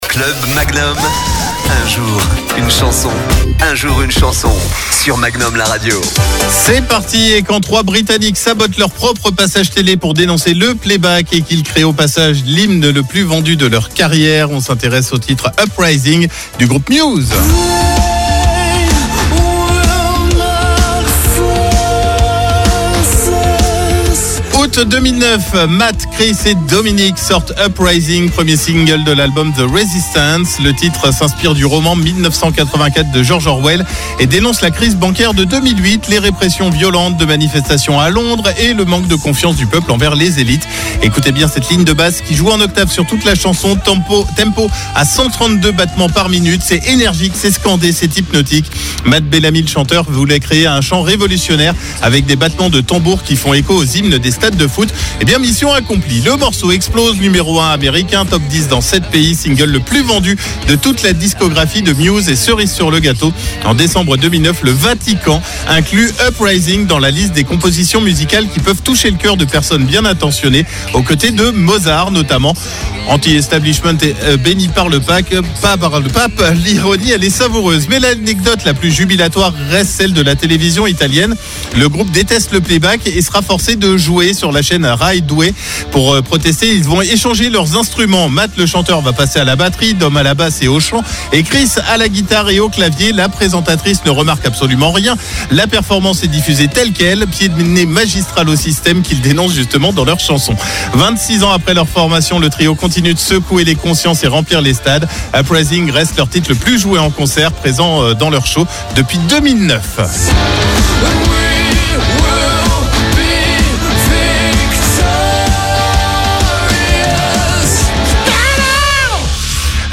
Le titre s'inspire du roman "1984" de George Orwell et dénonce la crise bancaire de 2008, les répressions violentes de manifestations à Londres, et le manque de confiance du peuple envers les élites. Écoutez bien cette ligne de basse qui joue en octaves sur toute la chanson, ce tempo à 132 battements par minute : c'est énergique, scandé, hypnotique.